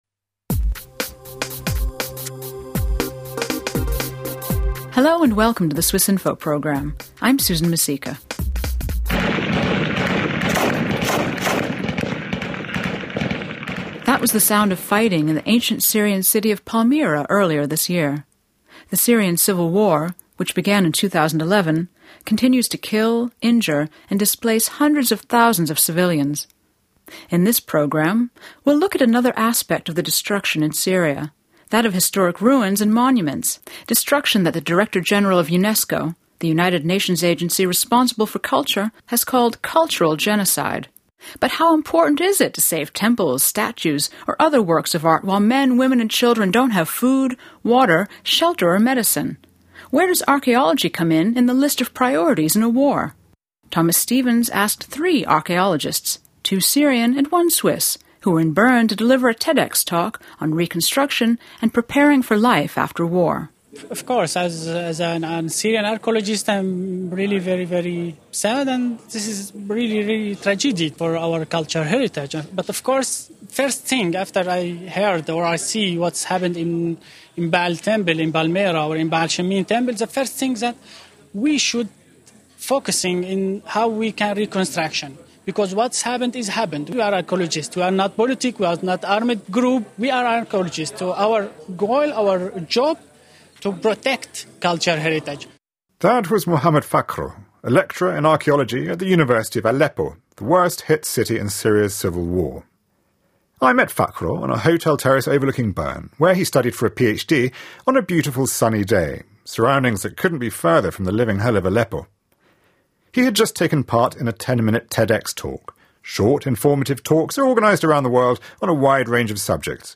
Three archaeologists explain the importance and challenges of rebuilding ancient Syrian heritage sites destroyed by Islamic State.